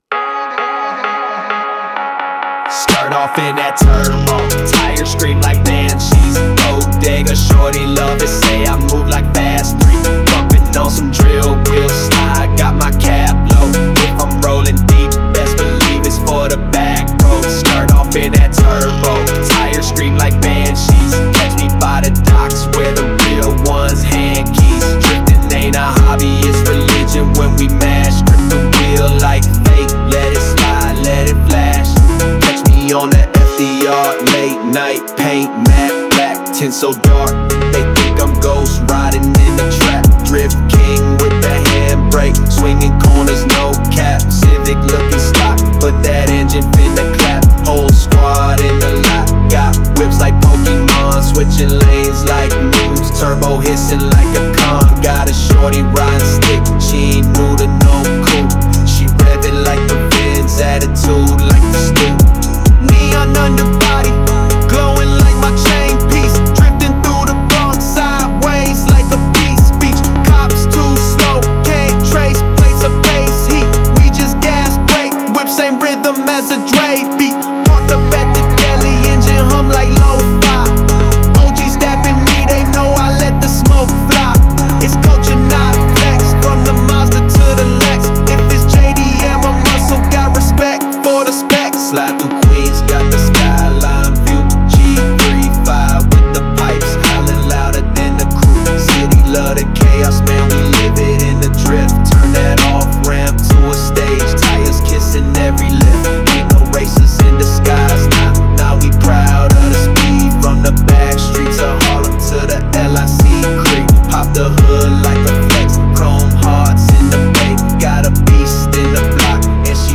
Текст песни ДИНАМИЧНАЯ МУЗЫКА Музыка